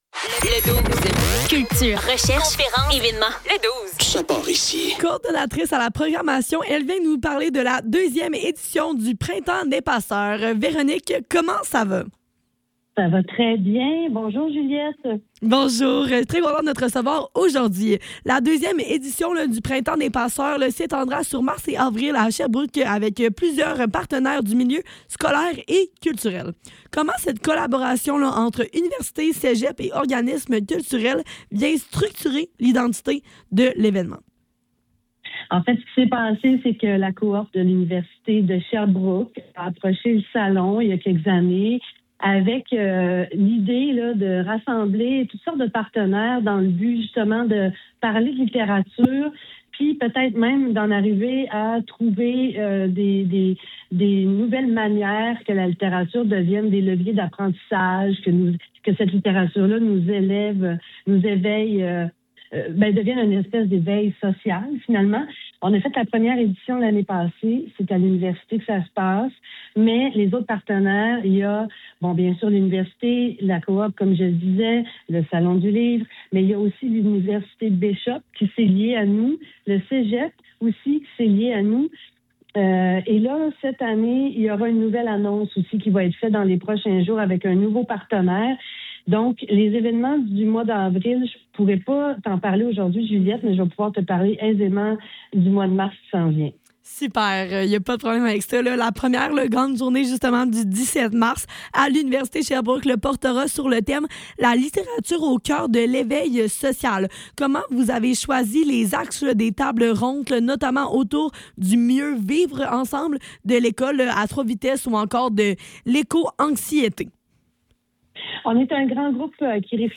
Le Douze - Entrevue